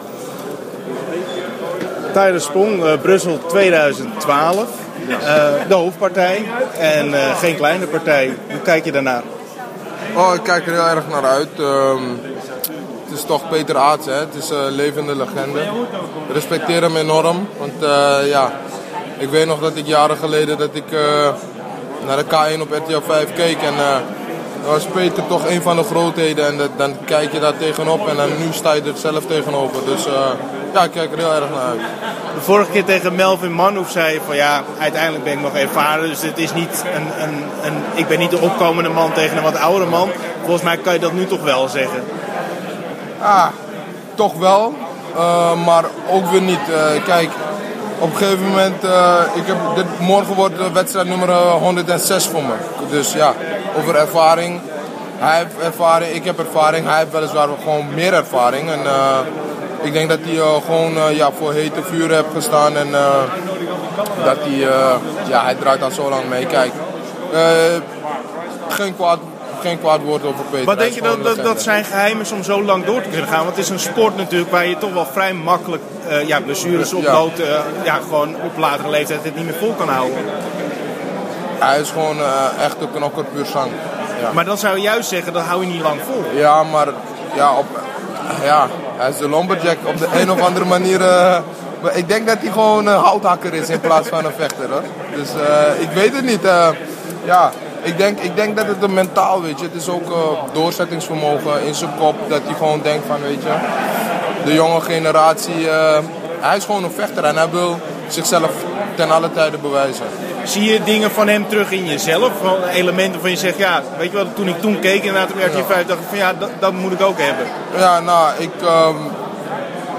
Interview Tyrone Spong 2012 juni in Brussel gewonnen tegen Peter Aerts